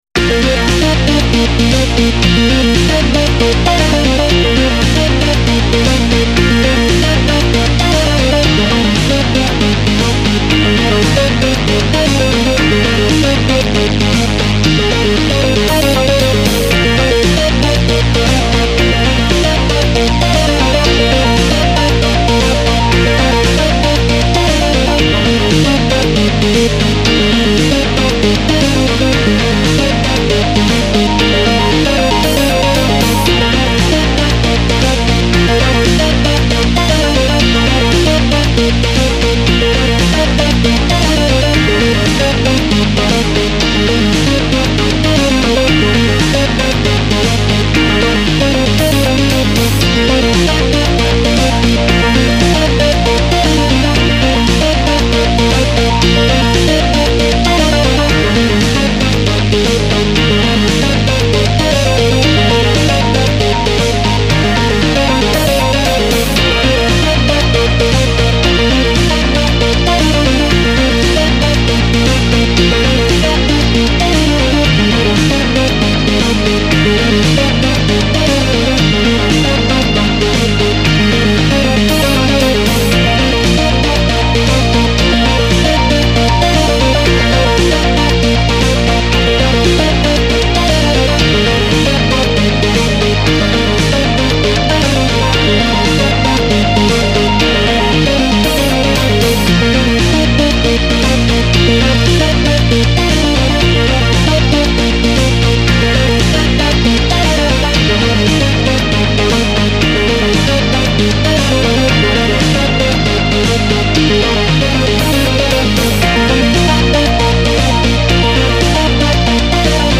Synth Beat/Degital BeatＢＧＭ
シンセ シンセベース ビート ＢＰＭ１１６